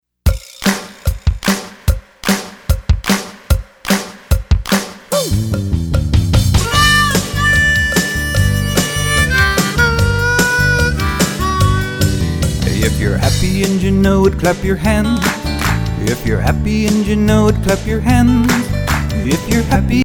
hand clappin', foot stompin' fun for everyone!